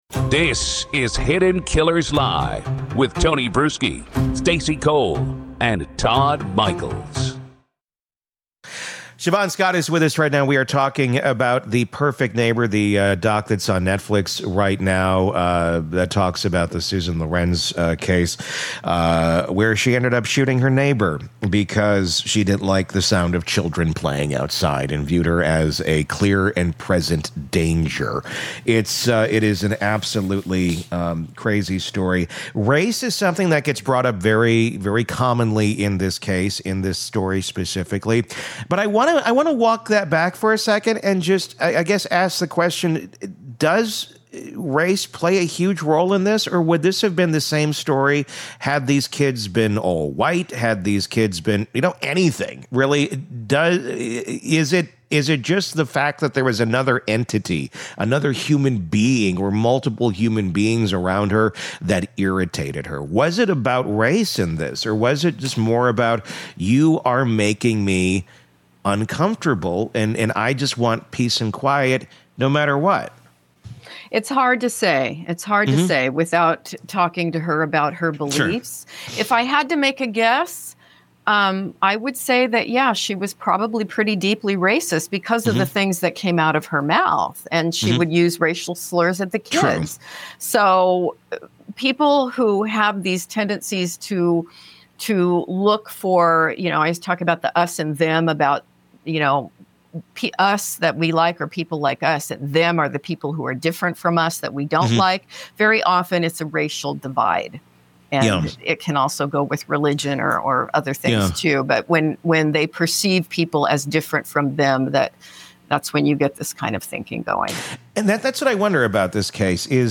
From micro-aggressions to the myth of the “good citizen,” this interview asks what happens when anxiety weaponizes itself and empathy disappears.